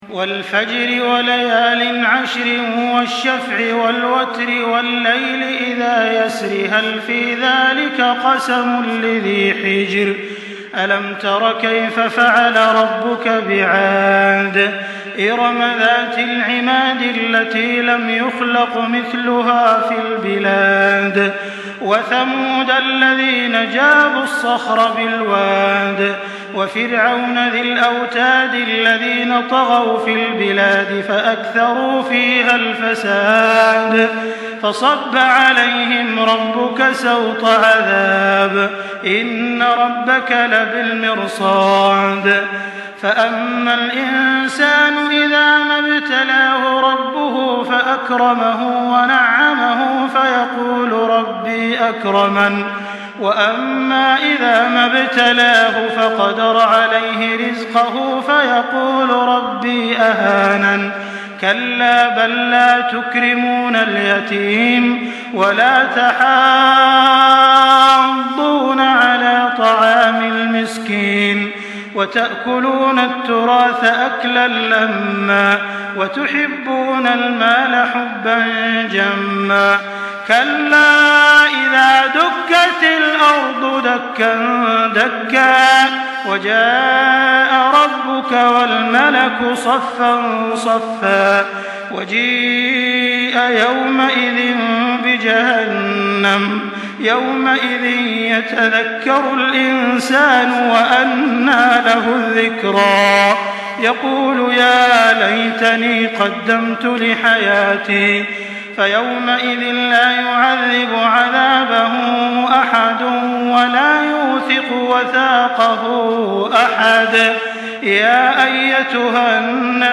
Surah الفجر MP3 by تراويح الحرم المكي 1424 in حفص عن عاصم narration.
مرتل حفص عن عاصم